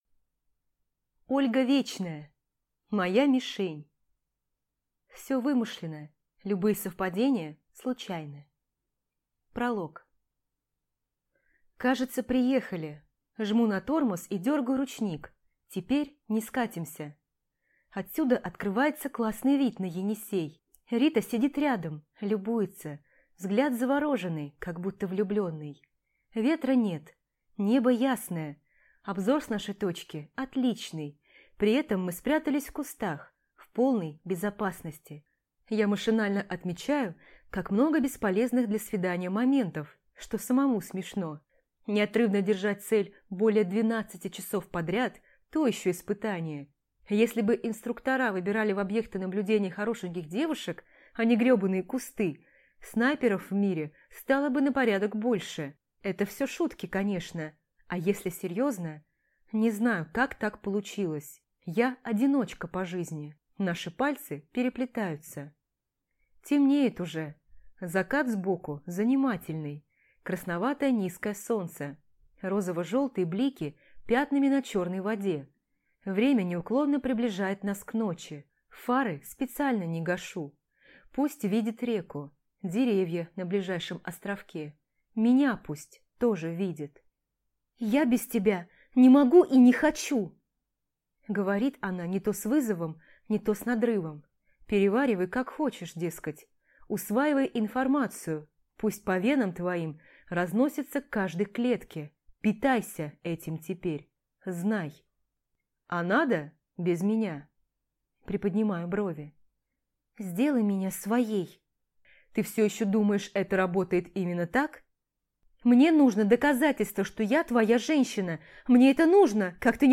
Аудиокнига Моя мишень | Библиотека аудиокниг